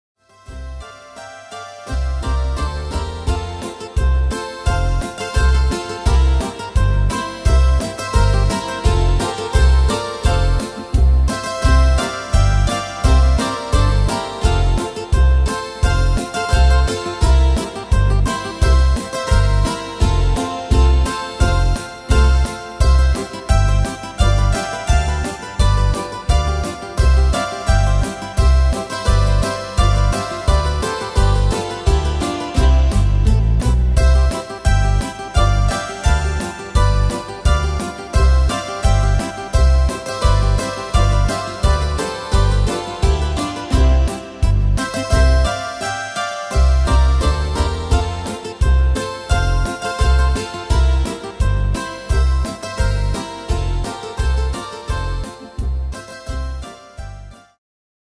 Tempo: 86 / Tonart: F-Dur